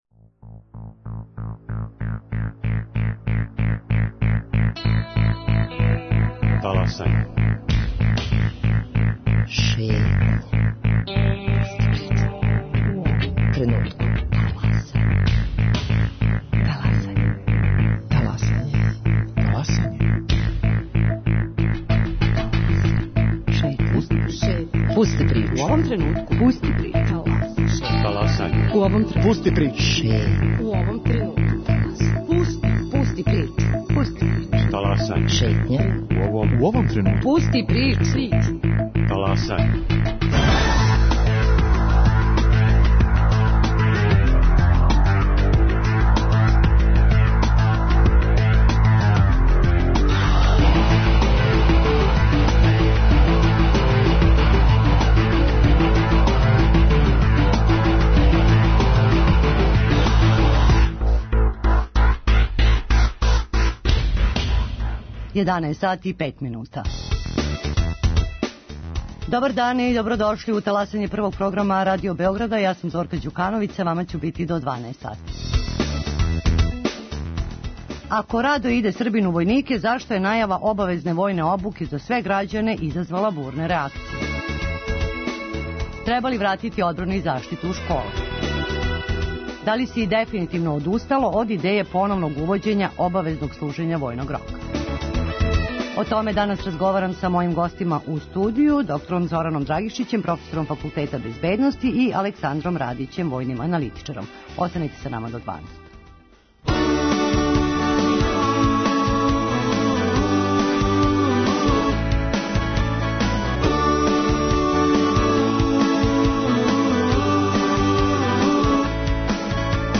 војни аналитичар.